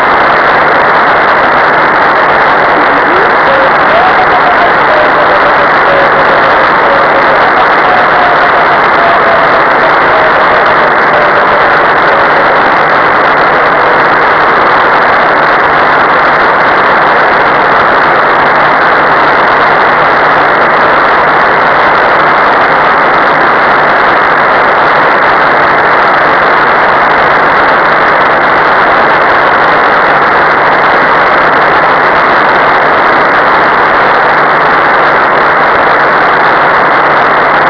In particolare abbiamo ascoltato molto bene i satelliti CO55 e CO57 (segnale morse ricevuto da CO55 - 8MB e